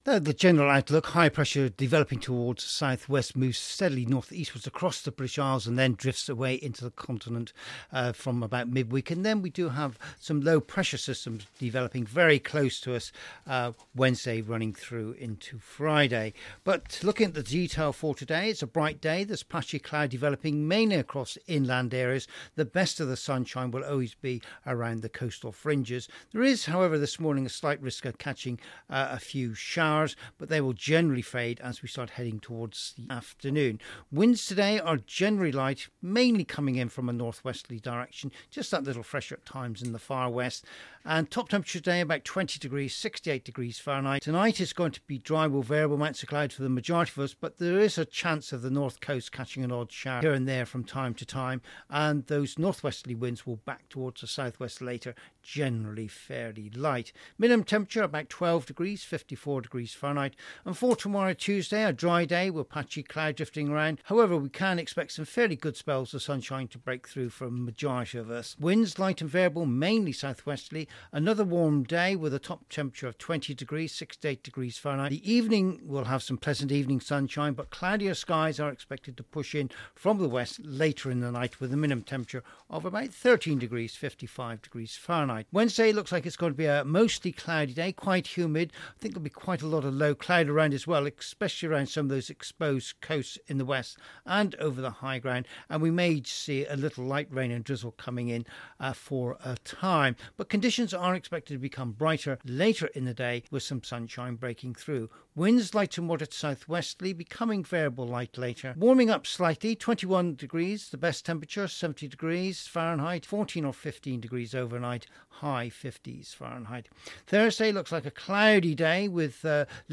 5 Day weather forecast for Cornwall and the Isle of Scilly